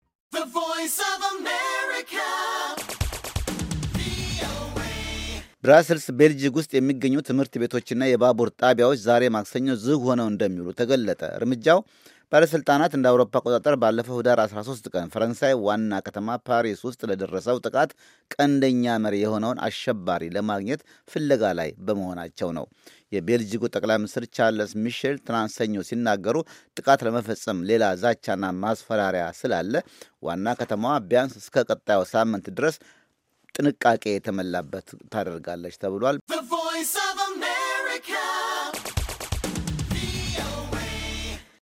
ይህ በእንዲህ እንዳለ፣ 130 ሰዎች በሞቱብ በፓሪሱ የሽብርተኞች ጥቃት ተጠርጣሪ የሆነው ሳልህ ኣብደሰላም (Saleh Abdeslam) ያለበትን በማግፈላለግ፣ የሞሮኮ ባለሥልጣት እገዛ እንዲያደርጉ በቤልጂግ መጠየቃቸውን አስታወቁ። የዜና ዘገባውን ከድምጽ ፋይሉ ያዳምጡ።